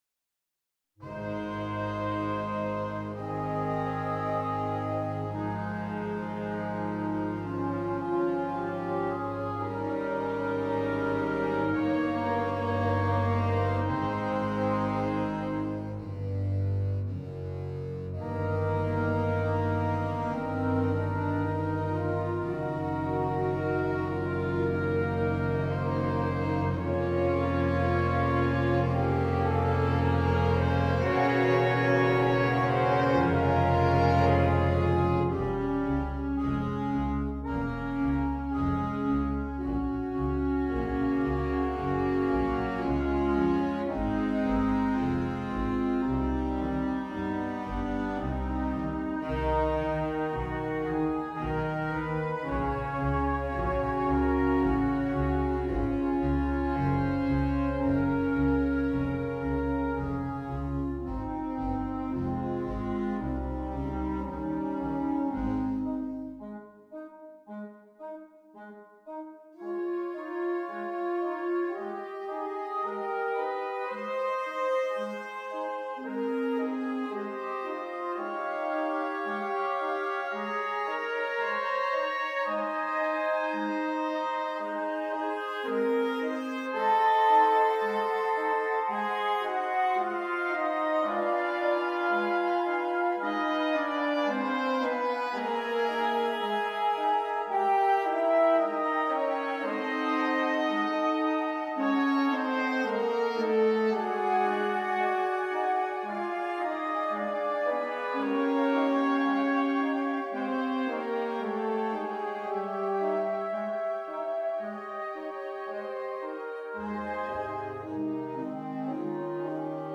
Symphony No. 2: 1892 Adagio The 1892 Doblinger version of the Adagio for concert band.